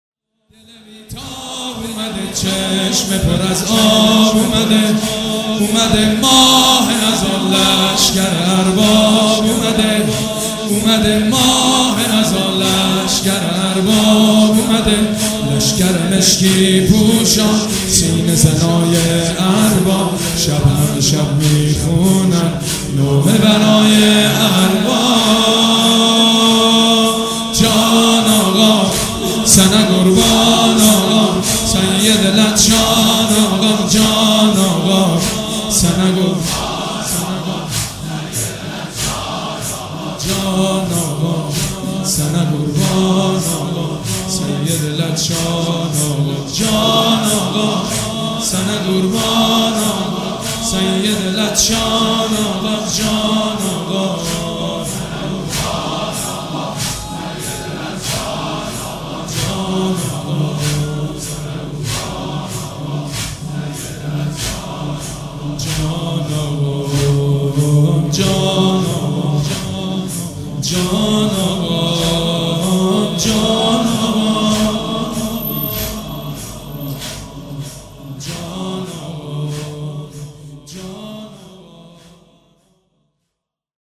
صوت مراسم شب چهارم محرم 1438هیئت ریحانة الحسین(ع) ذیلاً می‌آید:
بخش ششم-زمینه-به فدای تو دوتا دسته گلام ببین خواهشو توی بغض صدام